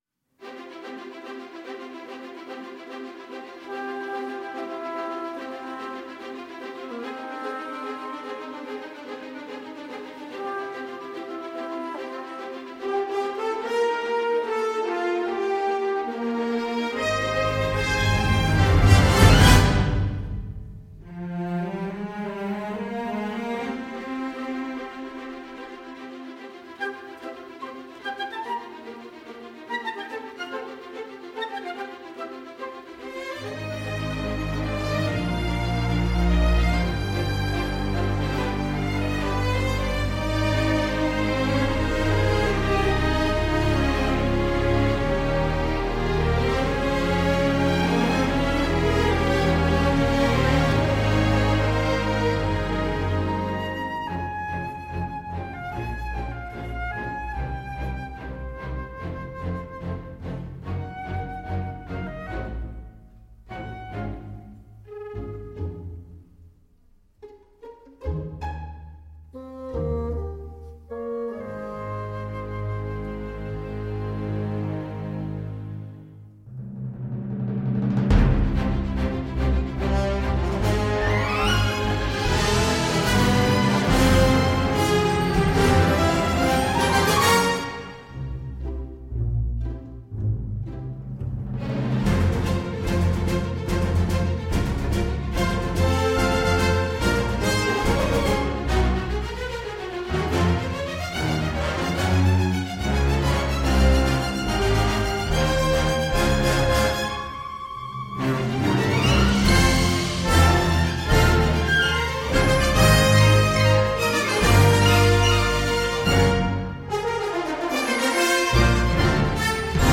Et ces vrombissantes Valkyries !